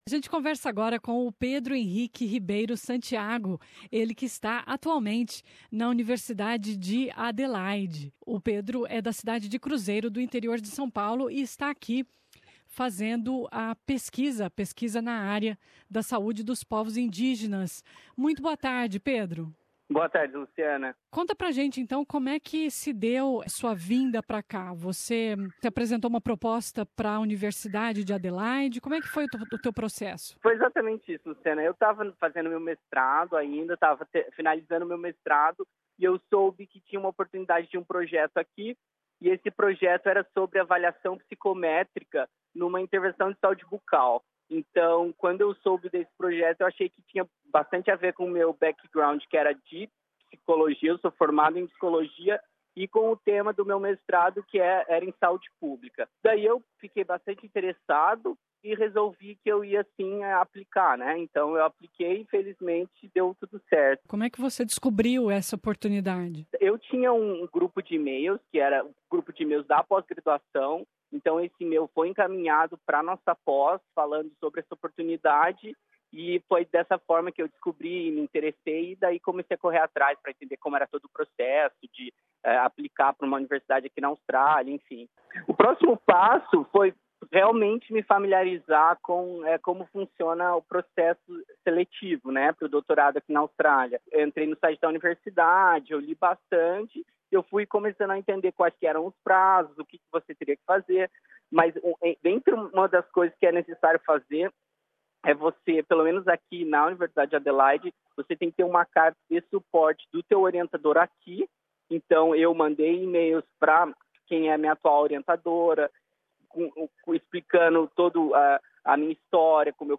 Nesta entrevista à SBS, eles falam sobre o processo, dão dicas de sites e explicam o que deu certo para eles.